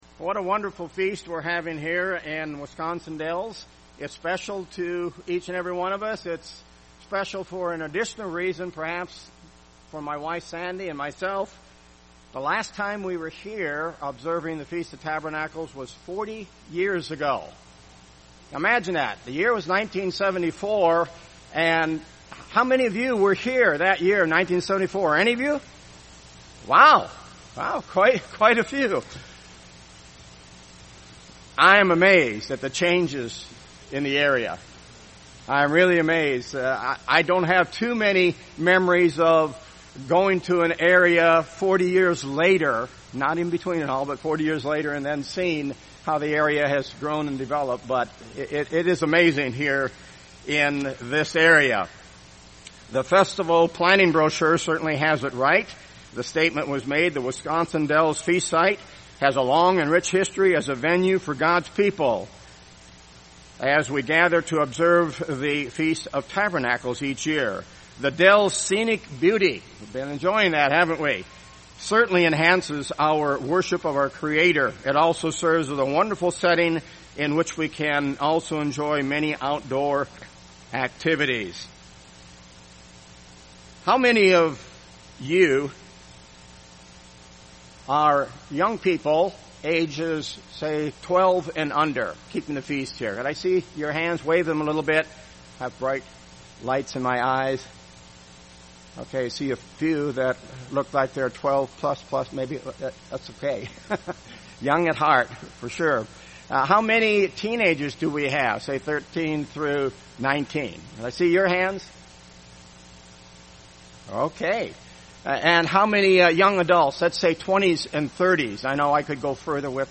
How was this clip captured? This sermon was given at the Wisconsin Dells, Wisconsin 2014 Feast site.